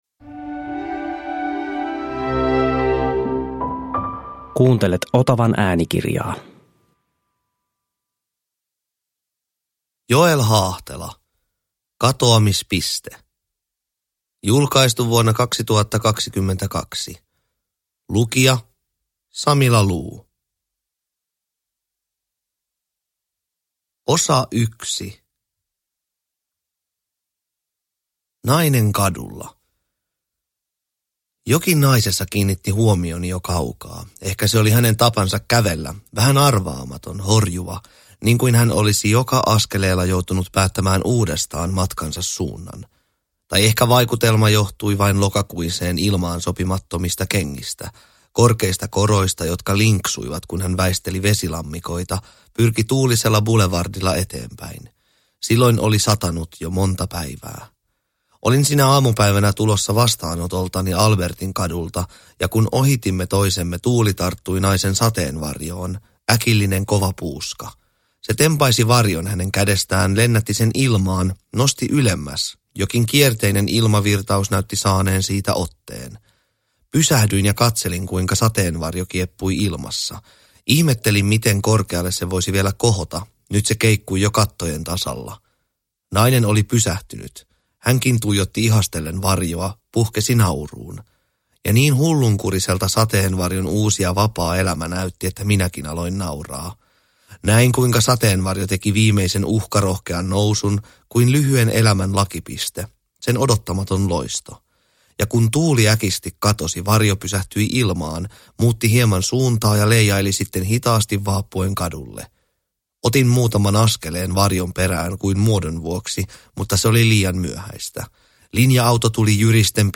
Katoamispiste – Ljudbok – Laddas ner